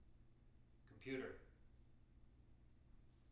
wake-word
tng-computer-330.wav